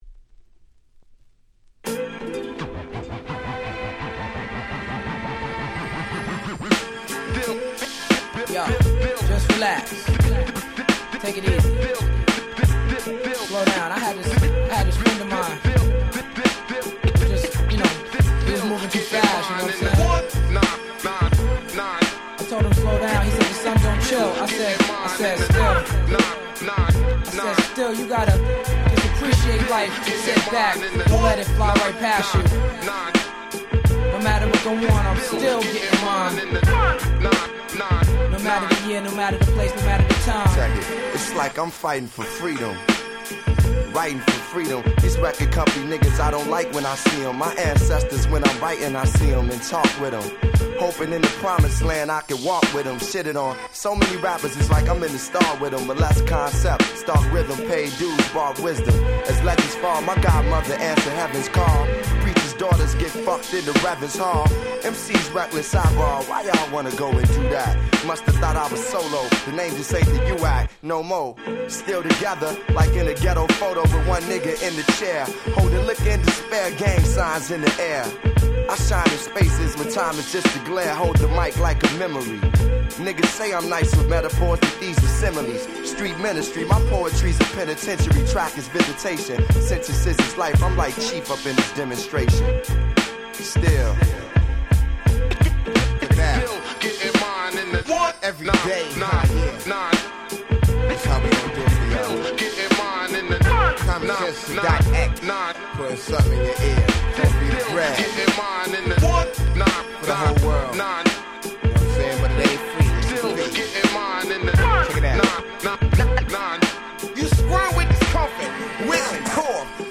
99' Smash Hit Hip Hop !!
90's Boom Bap ブーンバップ